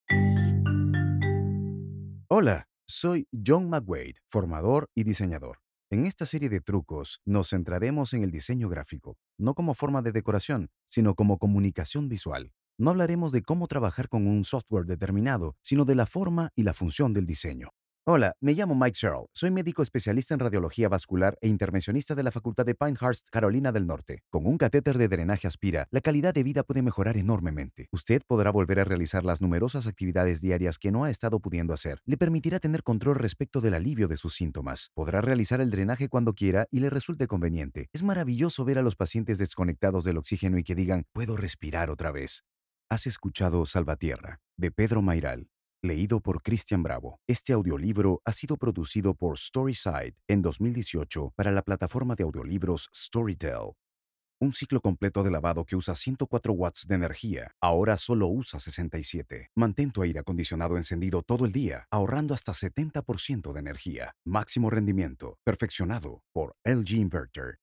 Hola! My voice has a neutral accent so the versatility in its use for all Spanish speaking is assured. Corporate, Romantic, Smooth, Warm and Deep Voice.
Sprechprobe: eLearning (Muttersprache):